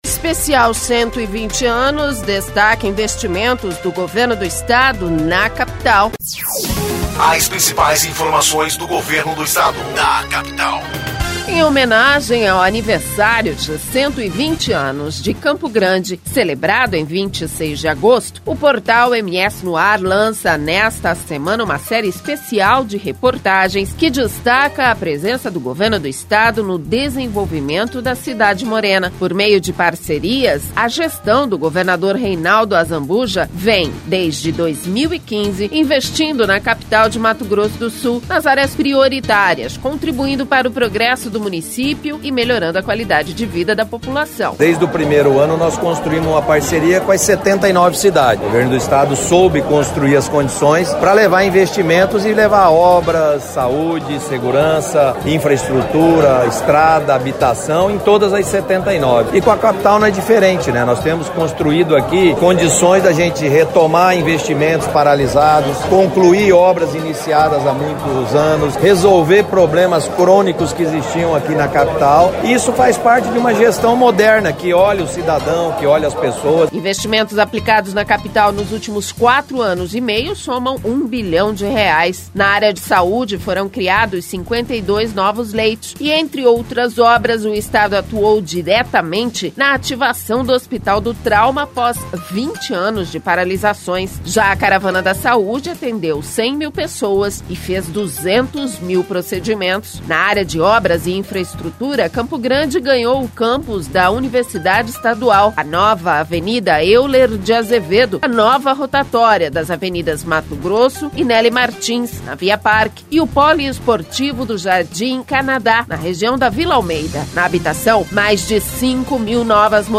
Além de ser veiculada no Portal MS, a série de reportagens especiais sobre os investimentos do Governo do Estado em Campo Grande, em comemoração aos 120 anos da cidade, será complementada por boletins produzidos pelo canal MS no Rádio.